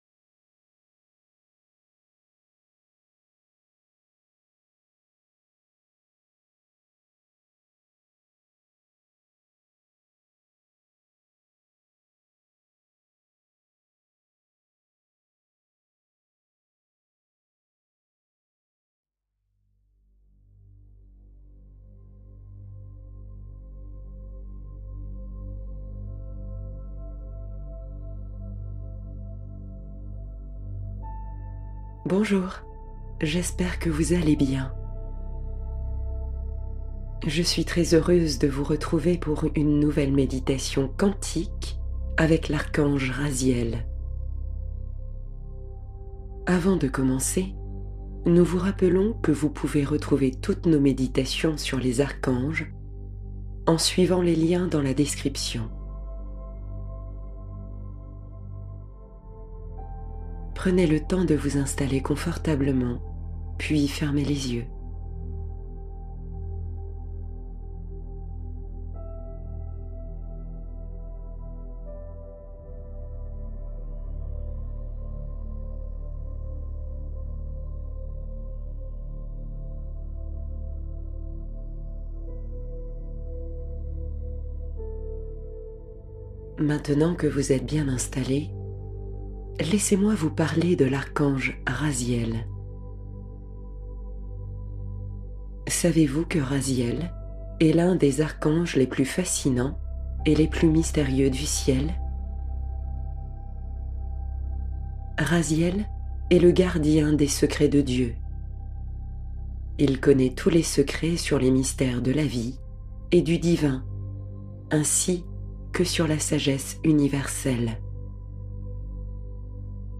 Un moment de réconfort — Méditation guidée pour se recentrer